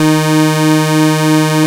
OSCAR 1  D#4.wav